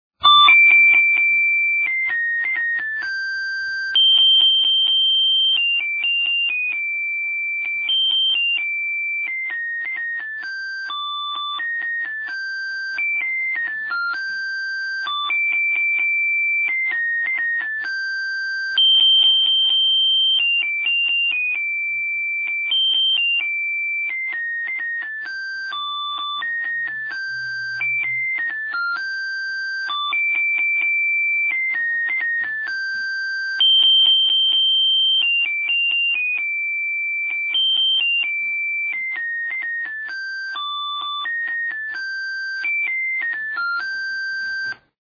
AC1501兰花草单音片是一颗洒水车通用的的音效芯片，常用于洒水车，语音提醒场合，可直接驱动喇叭和蜂鸣片，可根据不同音乐选择IC CODE。
单音兰花草声音
下载（样品录音，仅供参考，产品应用请实测）